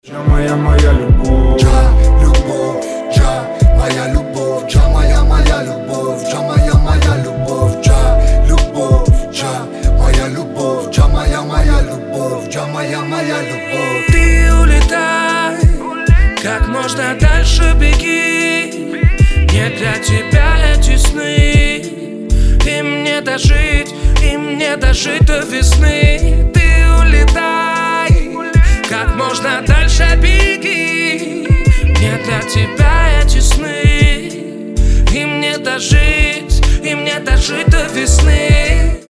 • Качество: 320, Stereo
мужской вокал
красивые
лирика
Хип-хоп
грустные
русский рэп